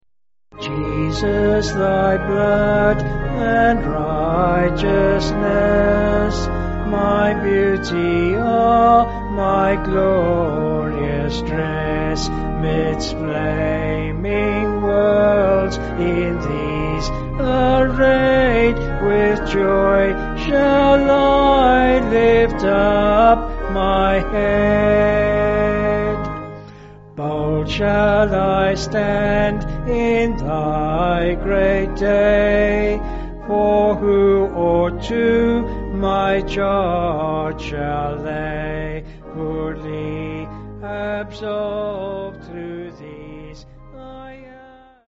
5/Ab
Vocals and Organ